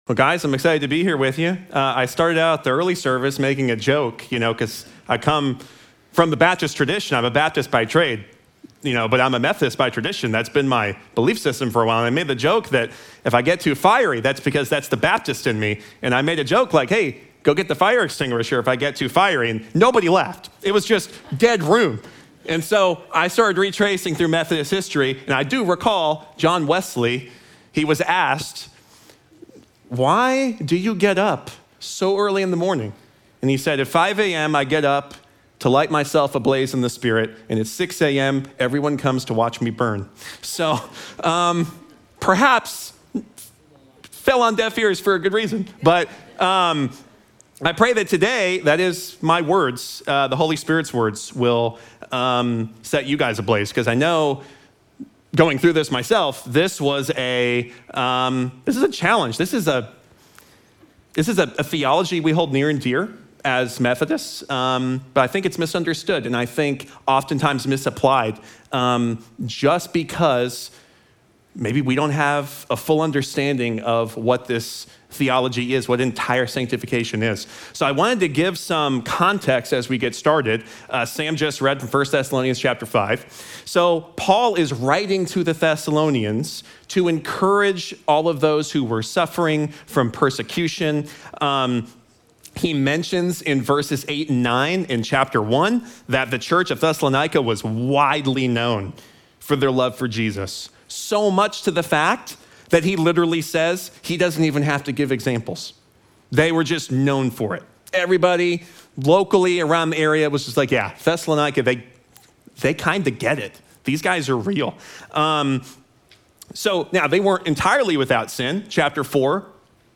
Sermon text: 1 Thessalonians 5:23-28